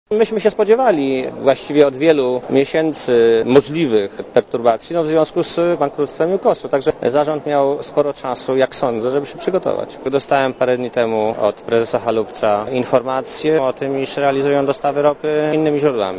Komentarz audio